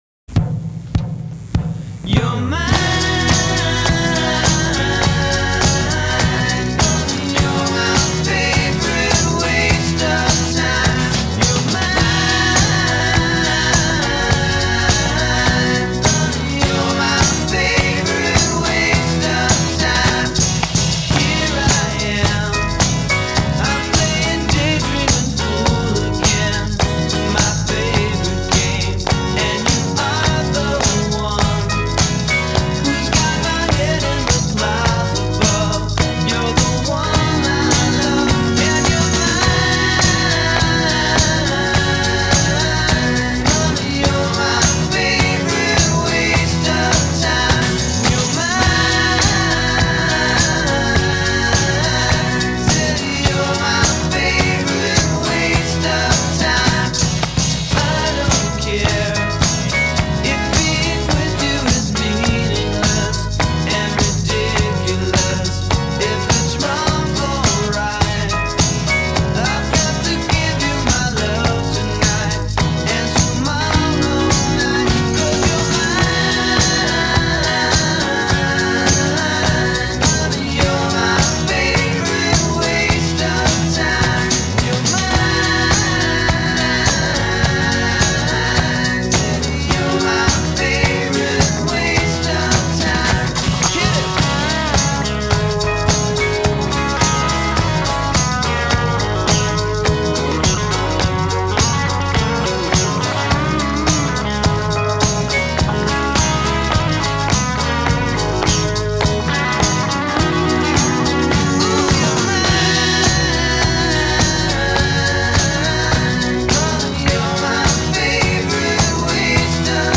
home demo
Still, the demo version is charming.